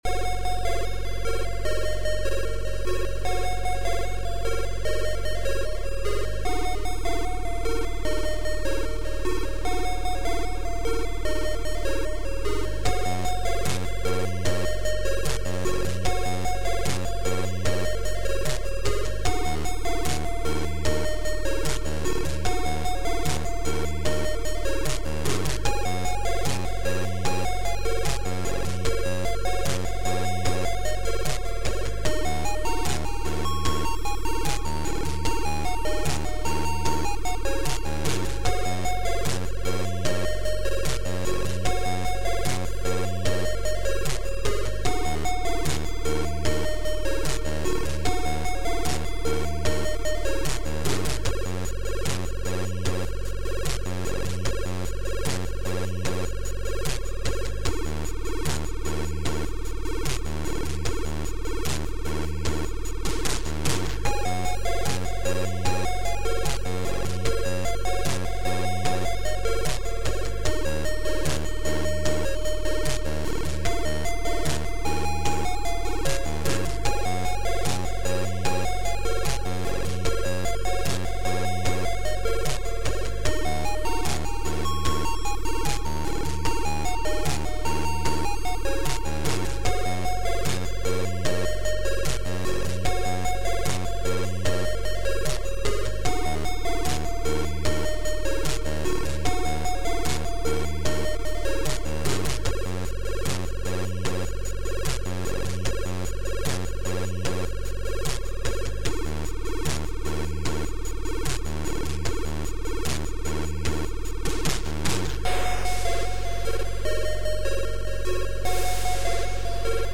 • An allocated channels for playing by default is ABC
ZX Spectrum + TS
• Два звуковых чипа AY-3-8912 / YM2149.